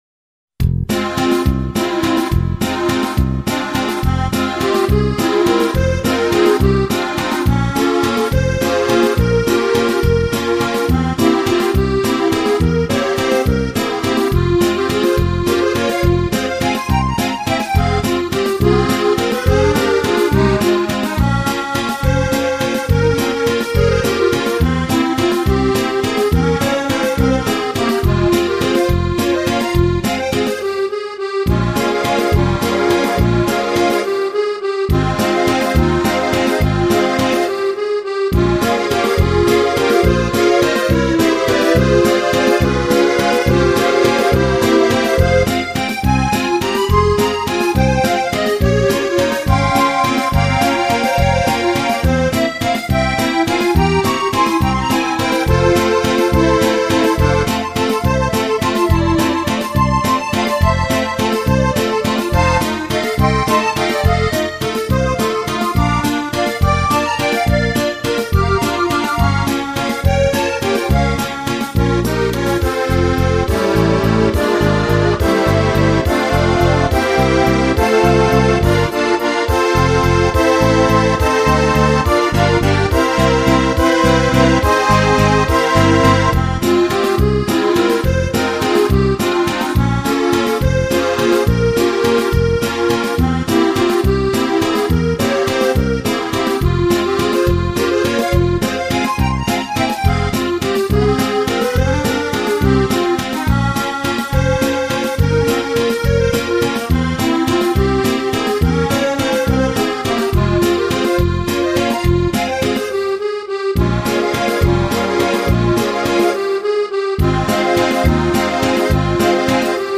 Вальс - танцевальная музыка для детей - слушать онлайн
Широко известная танцевальная мелодия для веселых танцев взрослых и детей.
vals.mp3